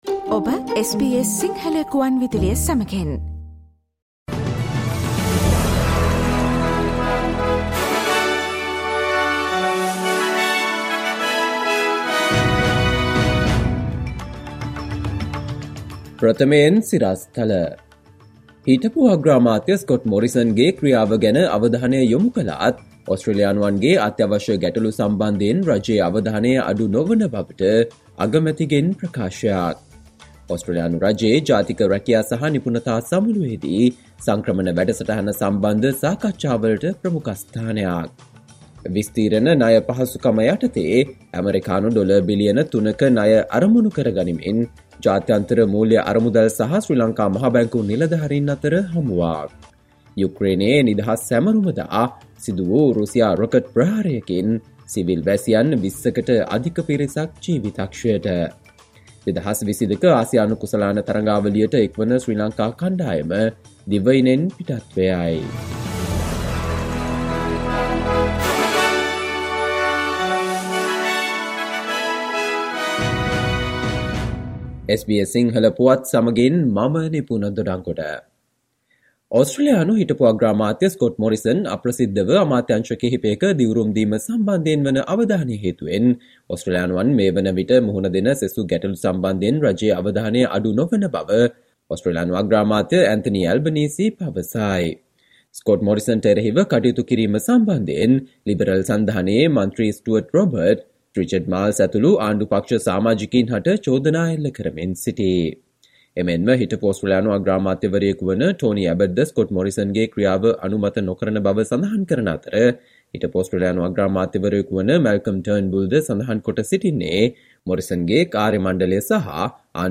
Listen to the SBS Sinhala Radio news bulletin on Thursday 25 August 2022